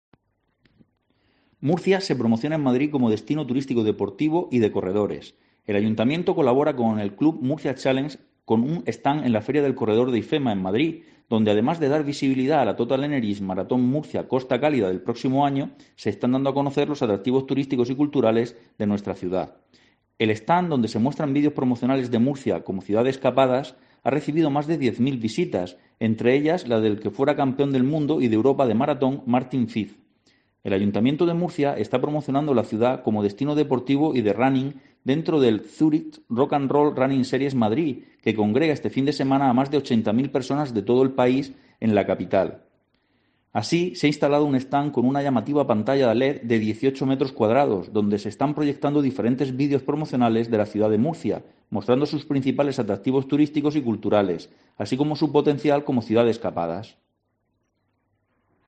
Jesús Pacheco, concejal de Turismo, Comercio y Consumo